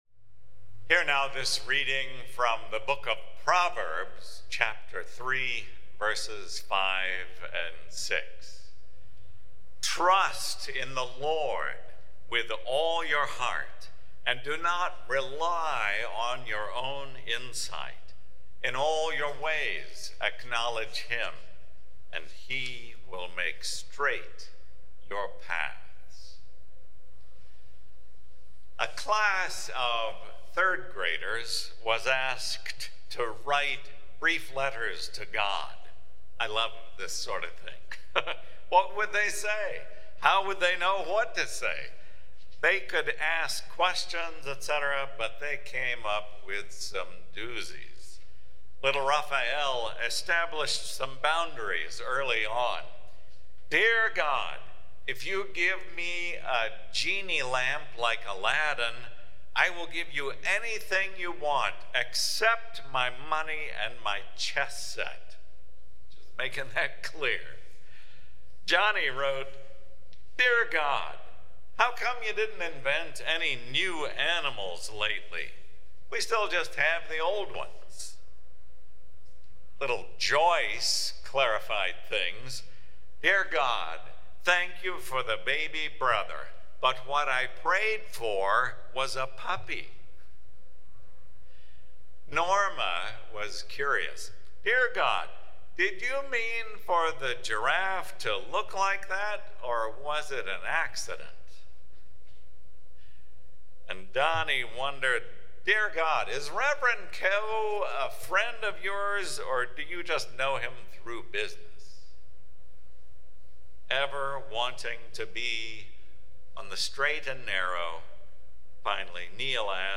Sermon Reflections: What does it mean to you to trust God "with all your heart"?